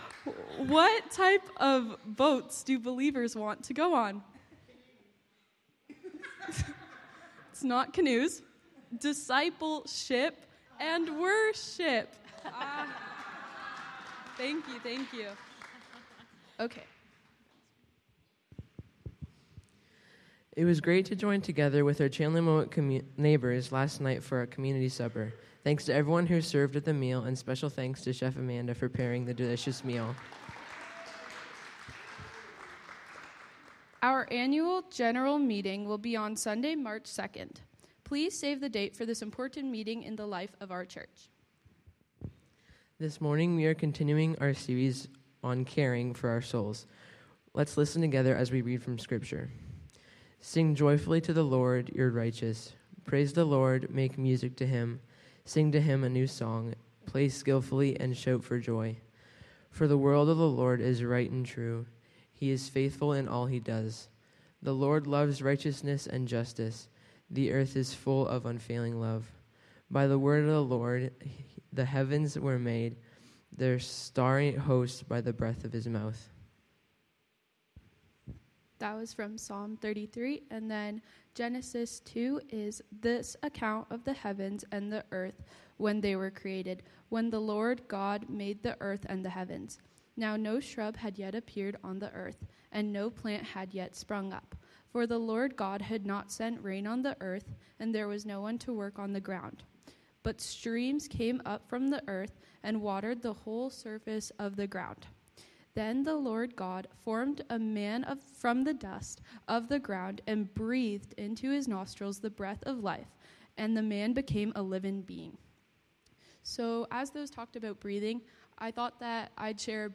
Sermons | The Gathering Church